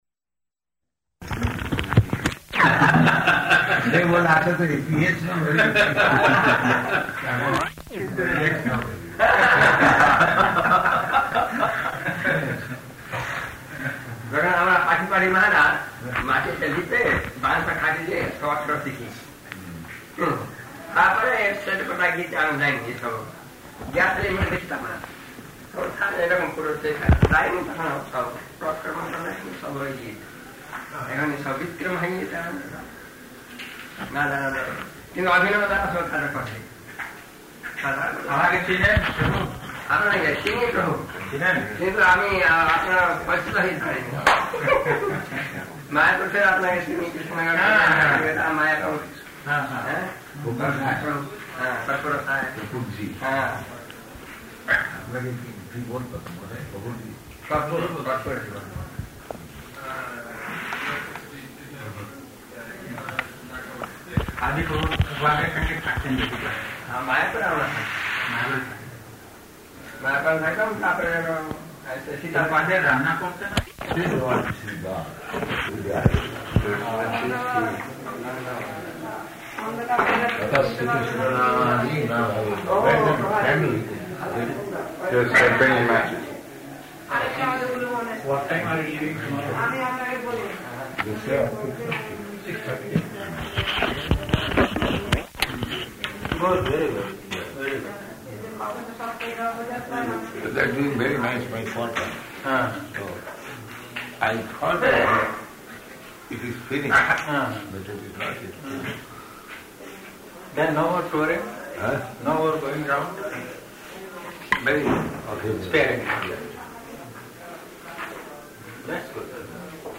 Room Conversation
Room Conversation --:-- --:-- Type: Conversation Dated: October 31st 1973 Location: Vṛndāvana Audio file: 731031R1.VRN.mp3 [Hindi conversation with guests, interspersed with laughter] Prabhupāda: ataḥśrī-kṛṣṇa-nāmādi na bhaved grāhyam indriyaiḥ [ Cc.